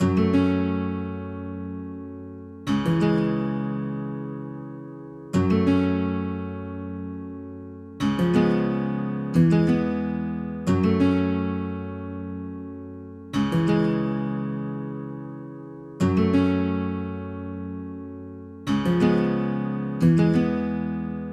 描述：F小调的原声吉他 Riff。带主音吉他合成器的层数1
Tag: 90 bpm RnB Loops Guitar Acoustic Loops 3.59 MB wav Key : Fm Ableton Live